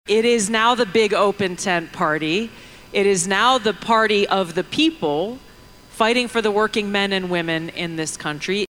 SPEAKING AT A RALLY IN MADISON, WISCONSIN … NOW-TRUMP TRANSITION TEAM MEMBER, TULSI GABBARD SPOKE ABOUT WHAT SHE FEELS THE G-O-P NOW REPRESENTS…